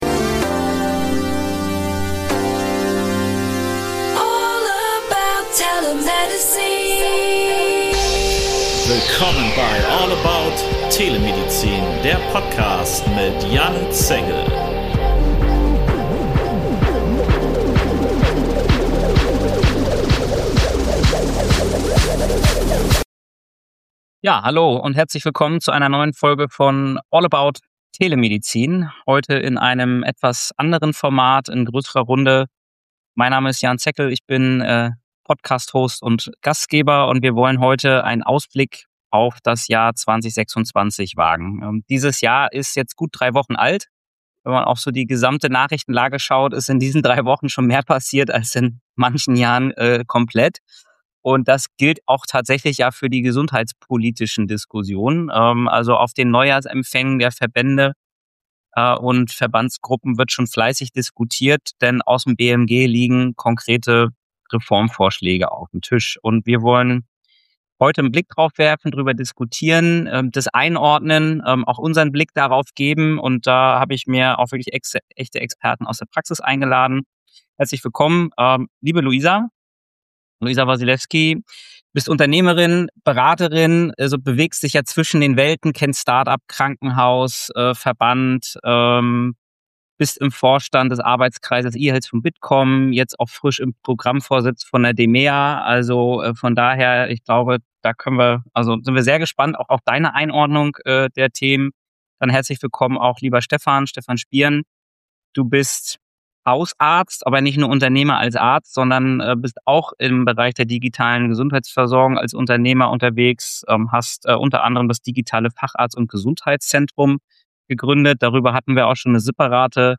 Eine ehrliche, praxisnahe Diskussion über Machtfragen, Steuerungshoheit und die zentrale Frage: Wie bringen wir das Gesundheitssystem wirklich ins Handeln?